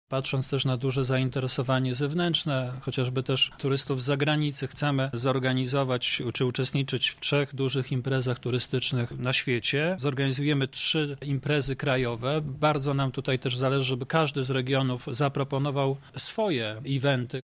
O szczegółach mówi Adam Jarubas – marszałek województwa świętokrzyskiego.